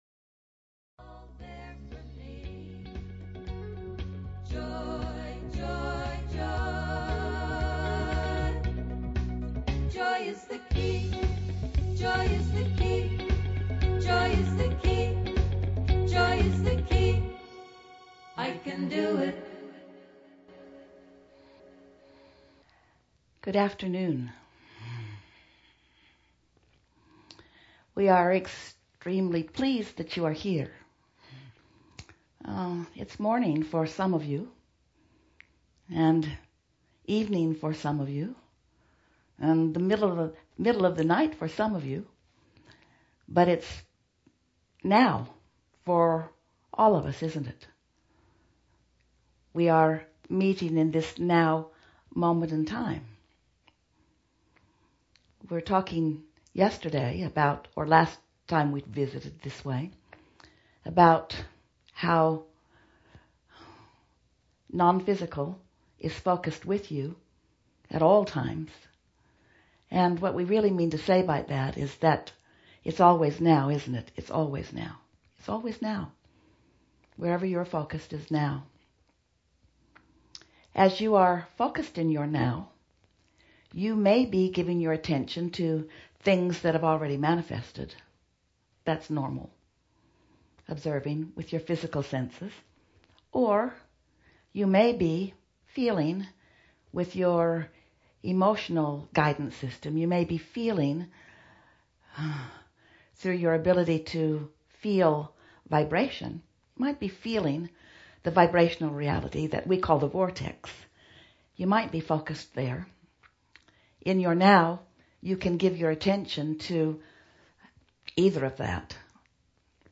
Live Recordings